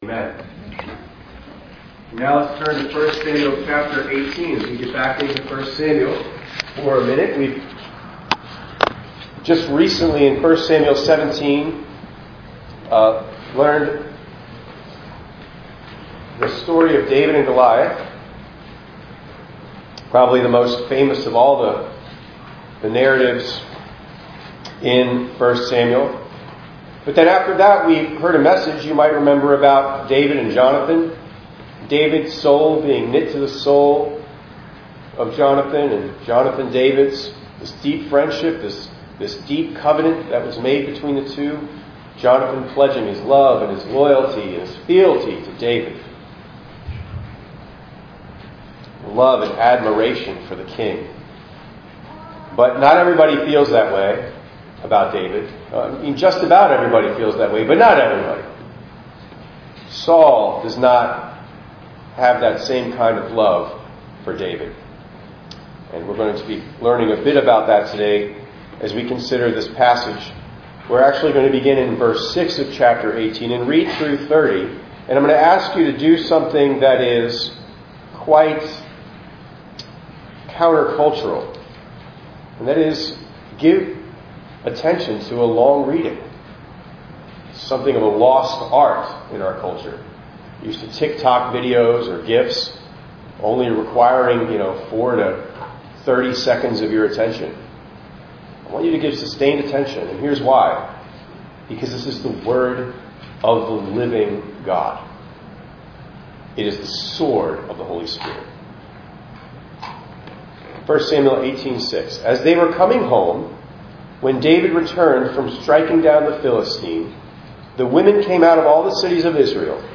6_29_25_ENG_Sermon.mp3